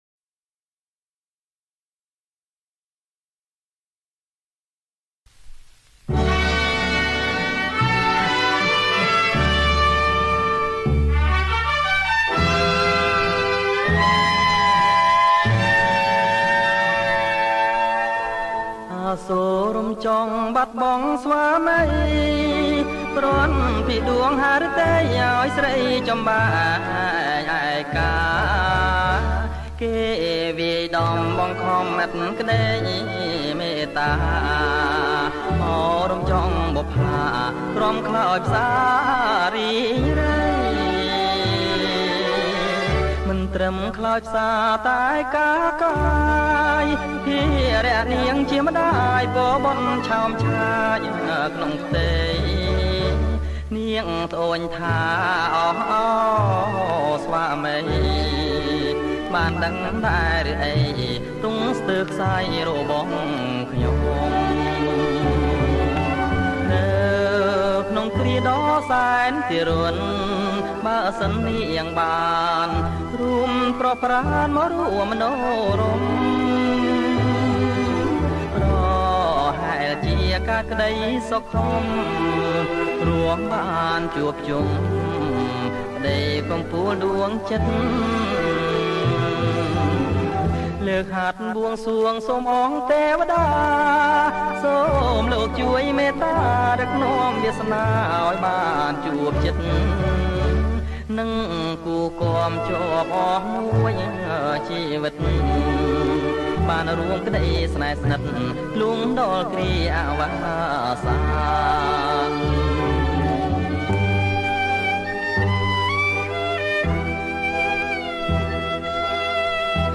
• ប្រគំជាចង្វាក់ Blue